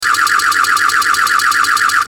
Sci Fi Laser Beam
Sci-fi_laser_beam.mp3